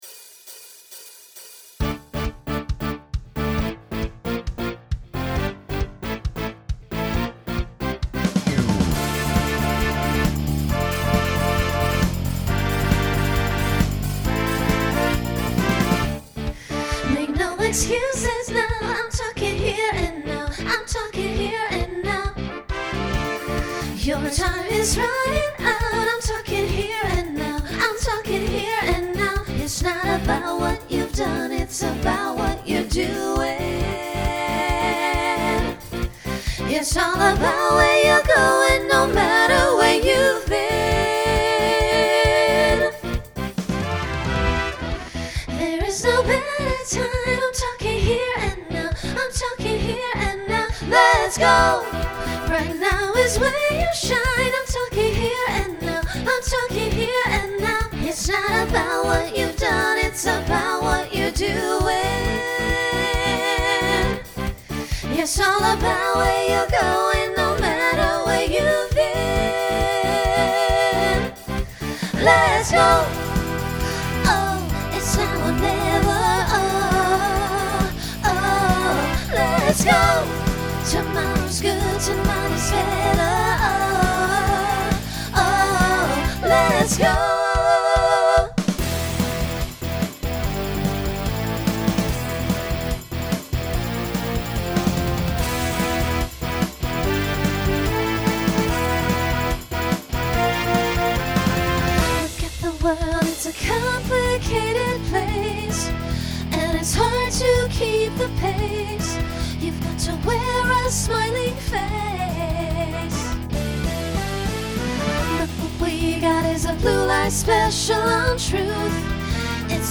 2010s Genre Pop/Dance , Rock
Voicing SSA